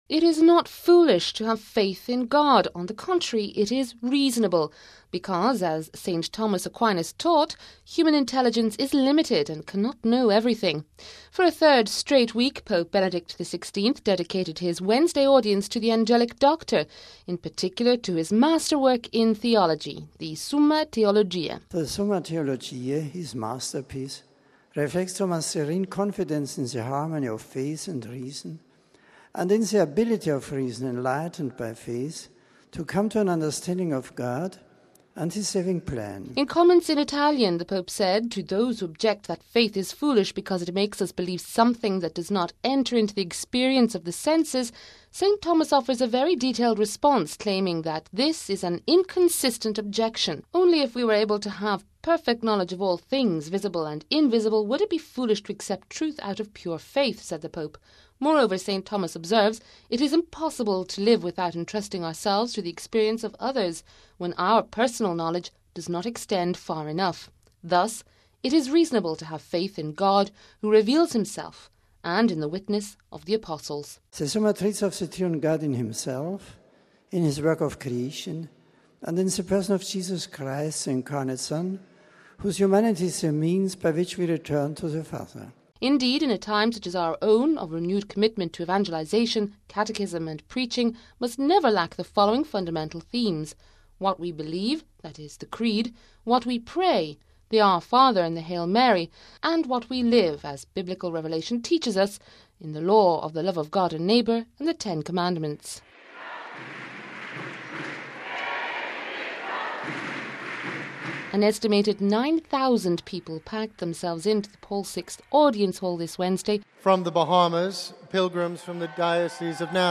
(23 Jun 10 – RV) It is not foolish to have faith in God, on the contrary it is reasonable, because, as St Thomas Aquinas taught, human intelligence is limited and cannot know everything. For a third week Pope Benedict XVI dedicated his Wednesday audience to the Angelic Doctor, in particular to his master work in theology, the “Summa Theologiae”.